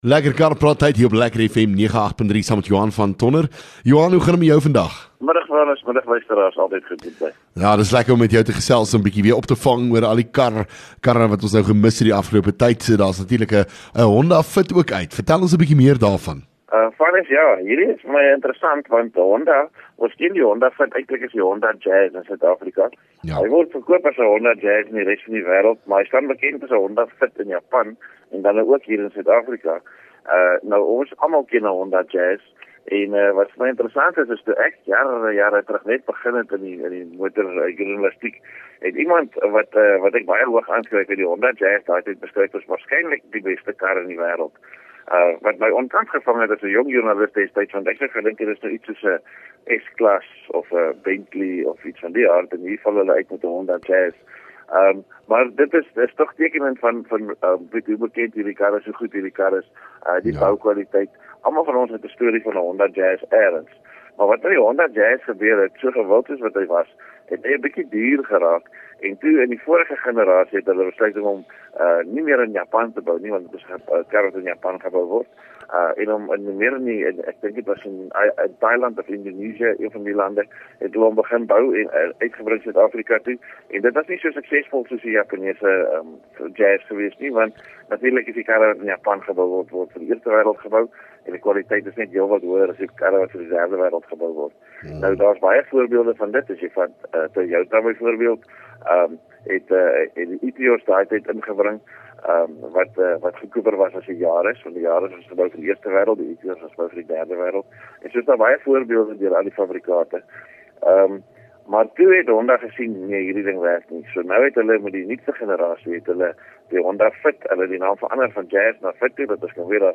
LEKKER FM | Onderhoude 16 Aug Lekker Kar Praat